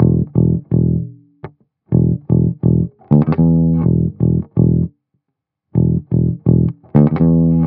07 Bass Loop E.wav